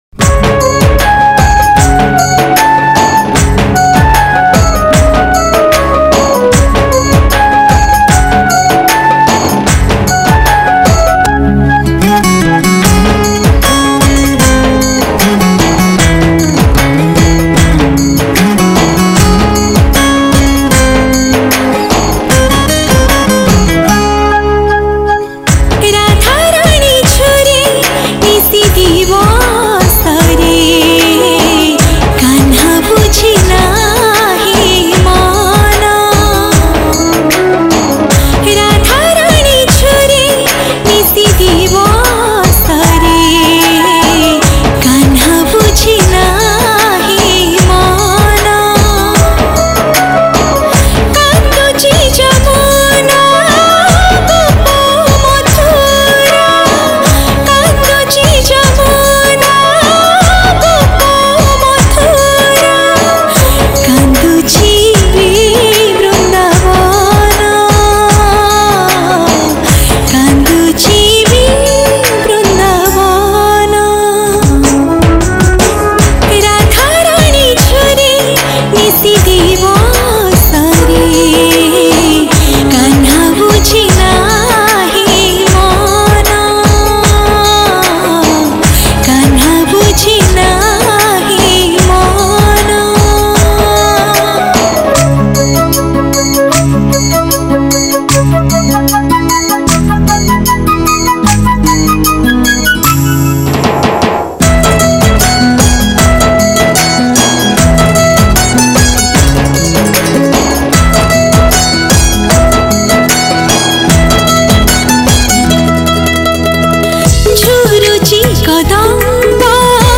Odia Devotional Song
Category: Odia Bhakti Hits Songs